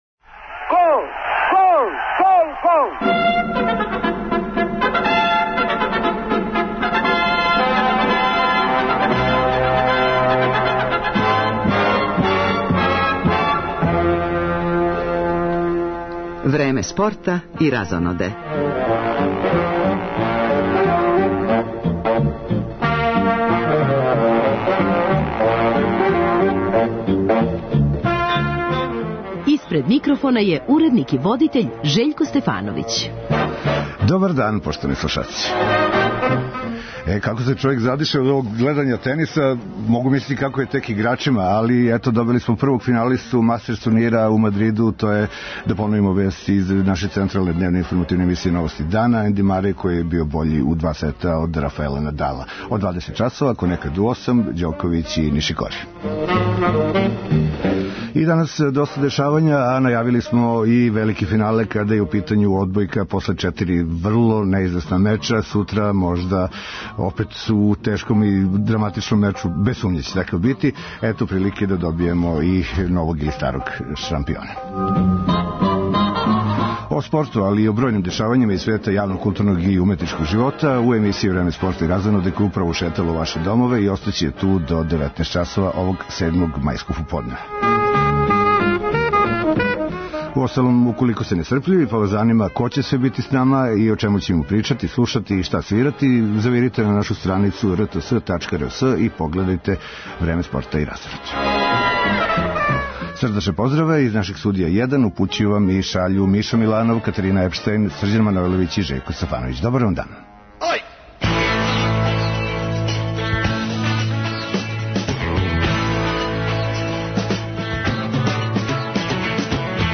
Бићемо у прилици да се медијски опростимо од Јадранке Стојаковић, подсетивши се њеног последњег интервјуа за ову емисију, тонски забележеног пре две године. Сутра пада одлука о прваку државе у одбојци.